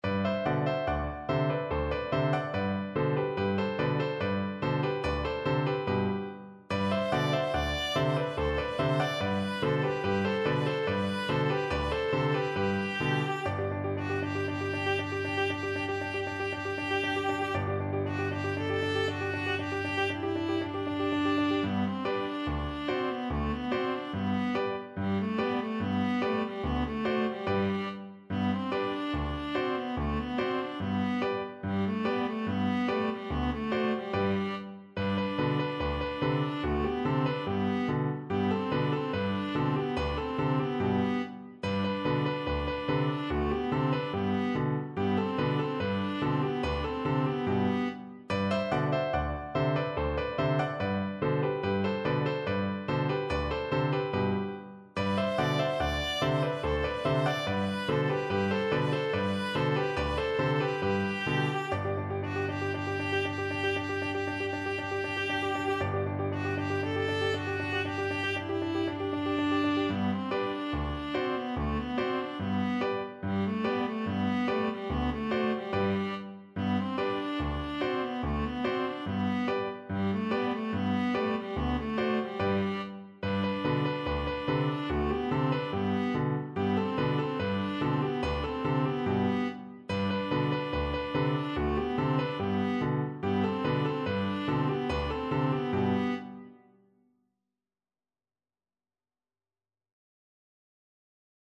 World Trad. Sikon (Opa Ni Na Nai) (Greek Folk Song) Viola version
Viola
G major (Sounding Pitch) (View more G major Music for Viola )
Cheerfully =c.72
4/4 (View more 4/4 Music)